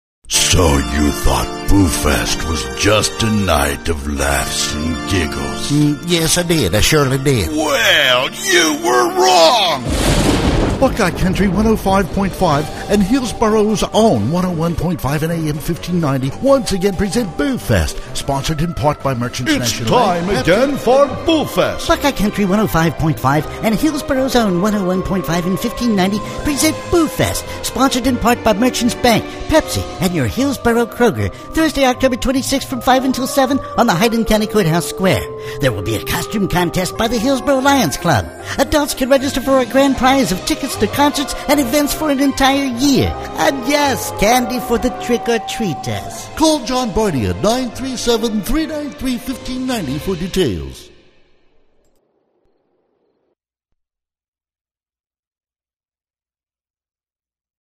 Male
My voice ranges from deep Bass to Baritone.
Halloween Radio Station Promo
Words that describe my voice are Deep, Southern, Cowboy.